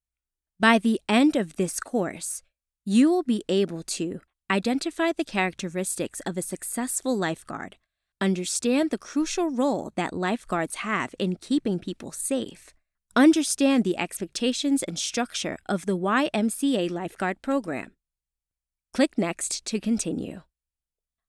Corporate Training
My voice is cheerful, youthful. bright, distinctive and versatile.